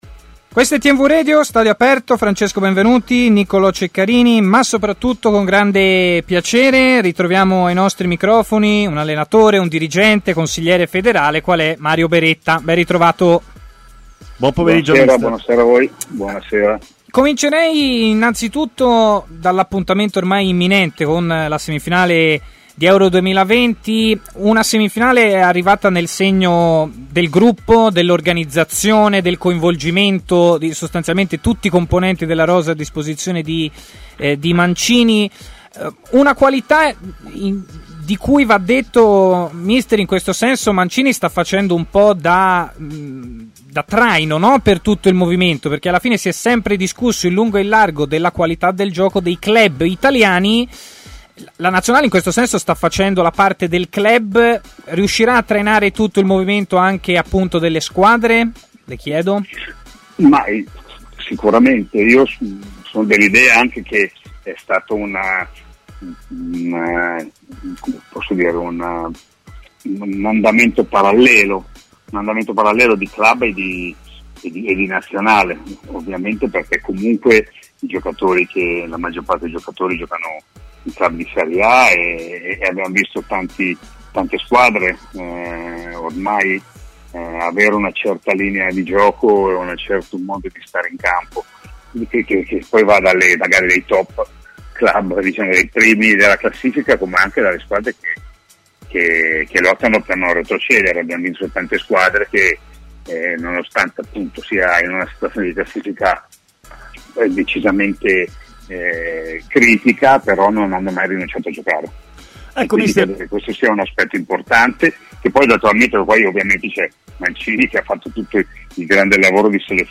trasmissione di TMW Radio